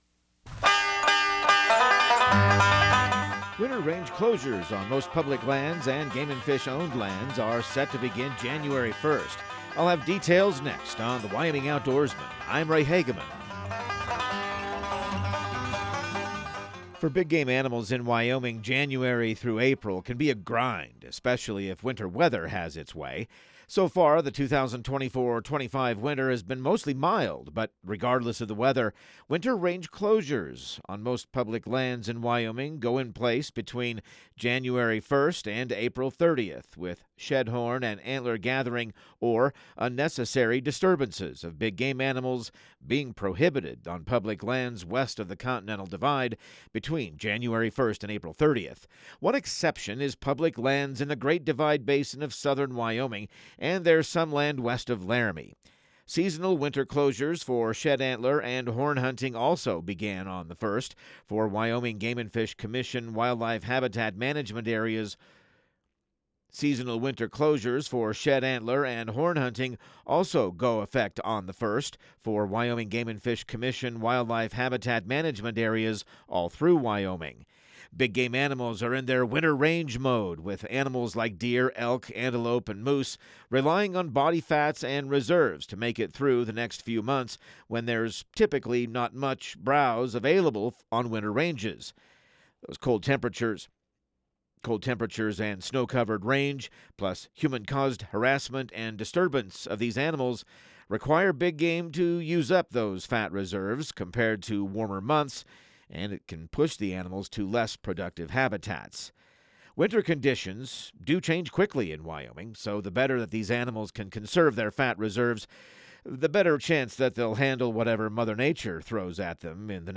Radio news | Week of December 23